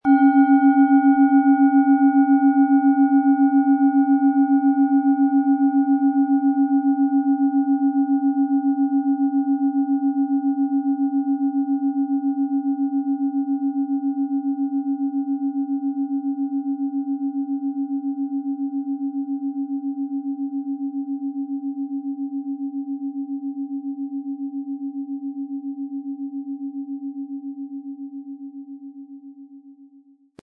Planetenschale® Wach und frisch fühlen & gestärkt werden mit Biorhythmus Körper-Ton, Ø 16,3 cm, 700-800 Gramm, inkl. Klöppel
Im Sound-Player - Jetzt reinhören hören Sie den Original-Ton dieser Schale.
Wohltuende Klänge bekommen Sie aus dieser Schale, wenn Sie sie mit dem kostenlosen Klöppel sanft anspielen.
SchalenformOrissa
MaterialBronze